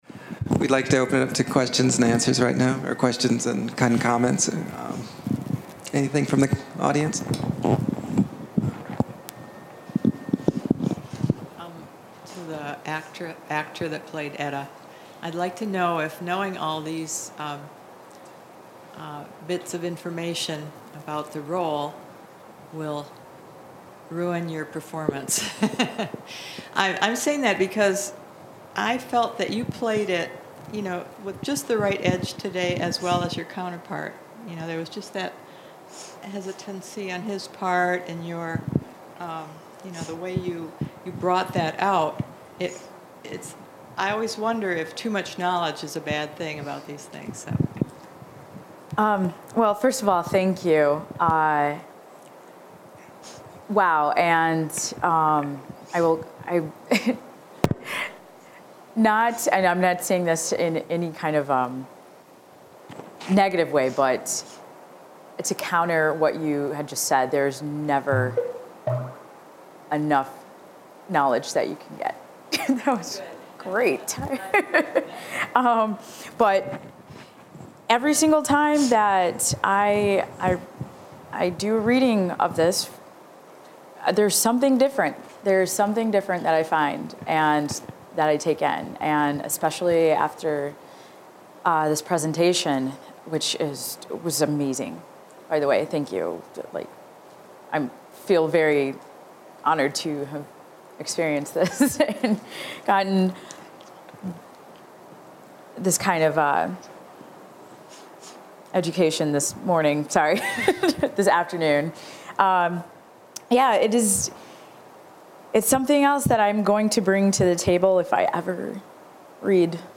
Held at the MSU Main Library.